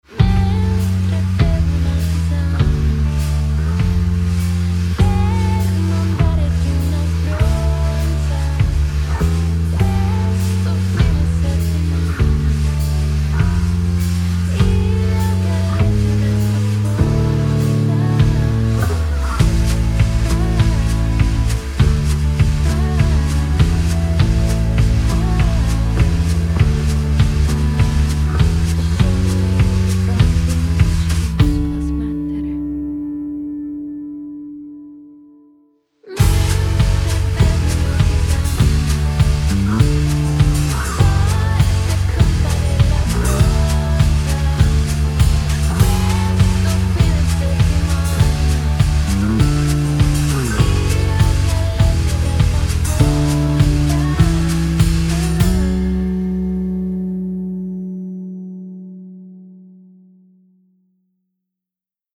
DRUMS_BASS
DRUMS_BASS-2.mp3